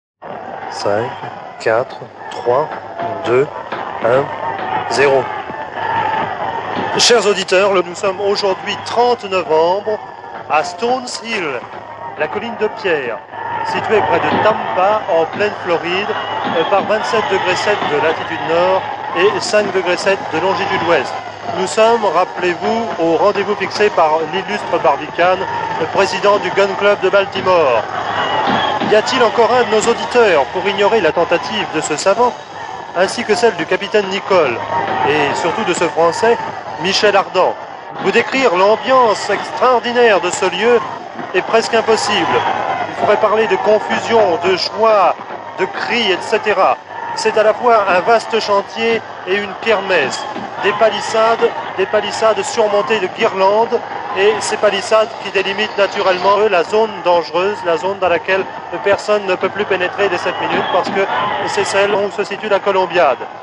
Diffusion distribution ebook et livre audio - Catalogue livres numériques
et 4 autres comédiens.